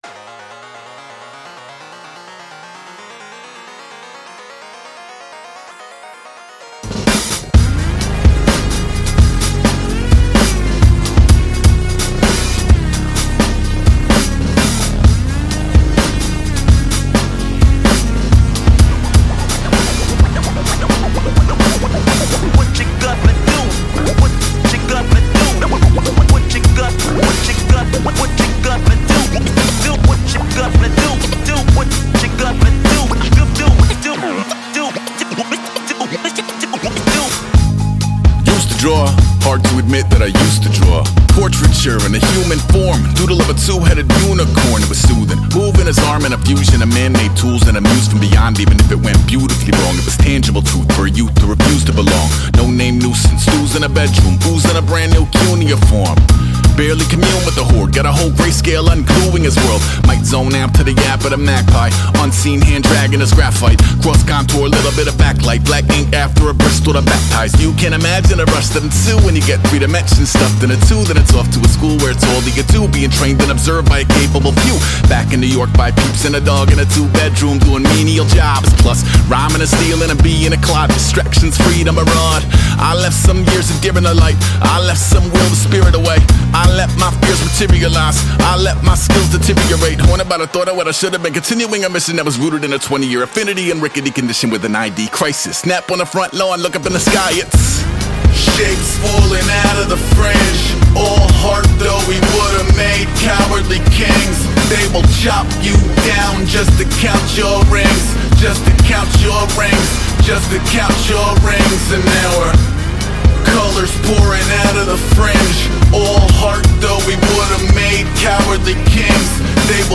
hip-hop
he raps in the refrain
and a contrast heavy beat